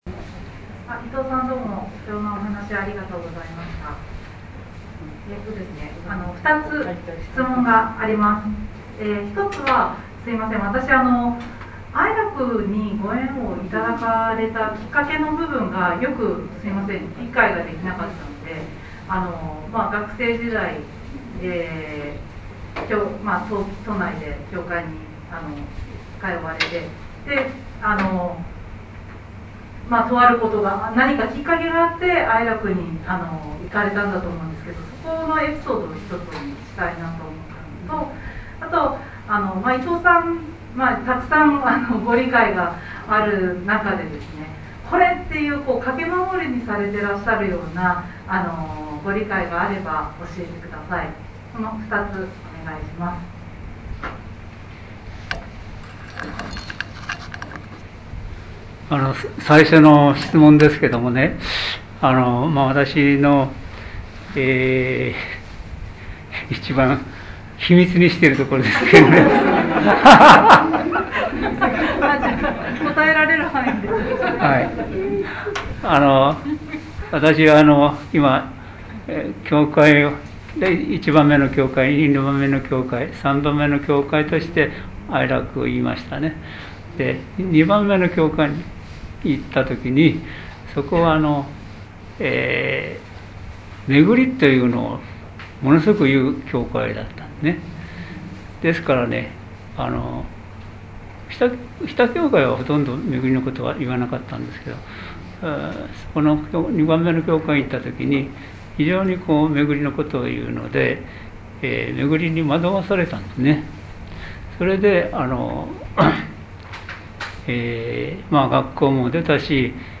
22.11.13 生神金光大神大祭･質疑応答